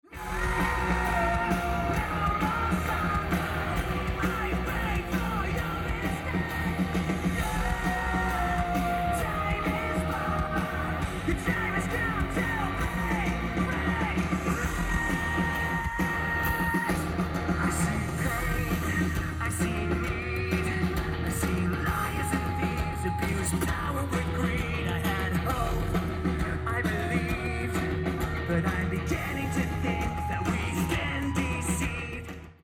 Venue Type:Outdoor